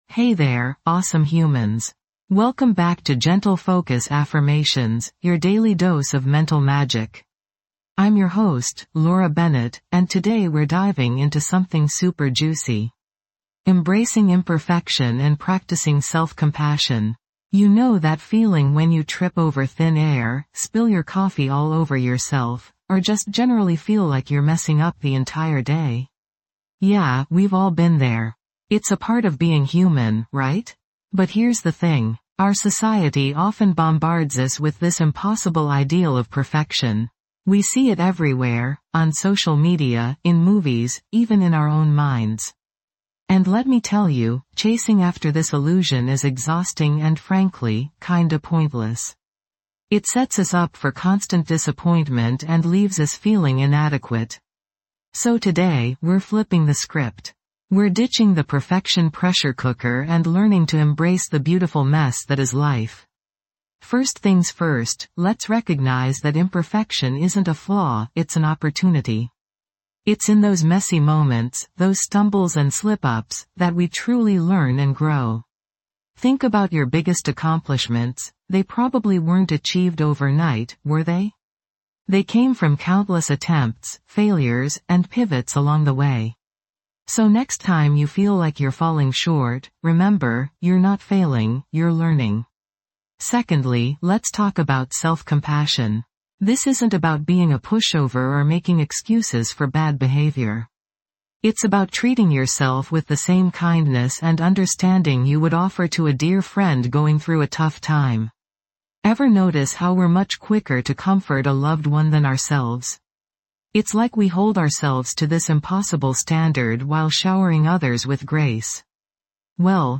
Best Soothing Voice Podcasts (2025)
(no music)
Hypnosis Session